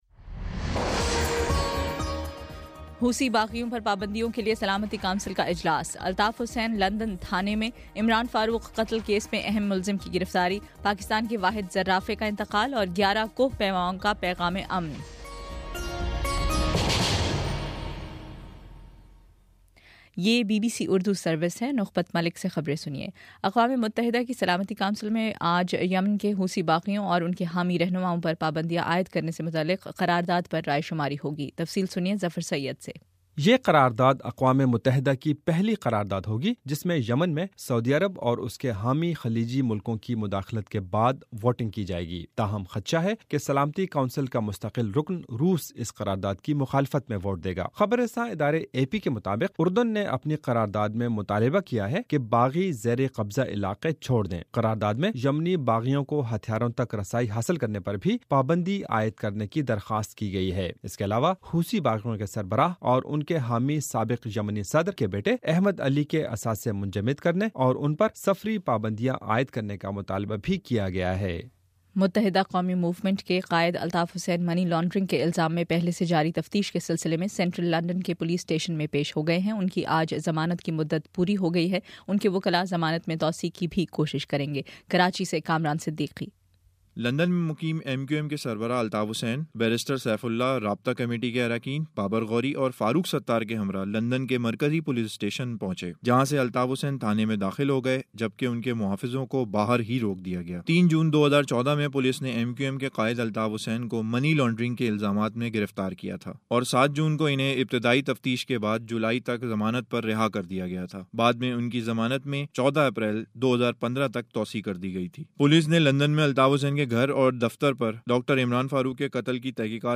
اپریل 14: شام چھ بجے کا نیوز بُلیٹن